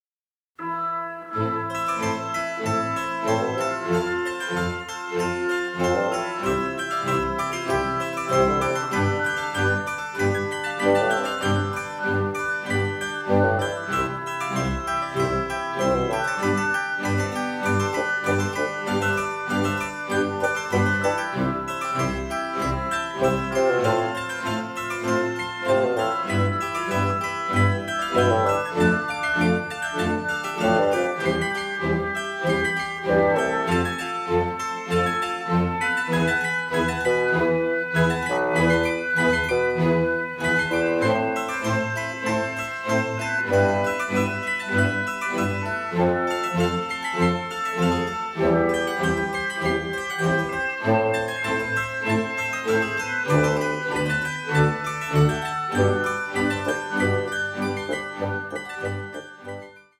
beautiful, nostalgic
radiantly passionate score